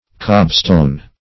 cobstone - definition of cobstone - synonyms, pronunciation, spelling from Free Dictionary Search Result for " cobstone" : The Collaborative International Dictionary of English v.0.48: Cobstone \Cob"stone`\, n. Cobblestone.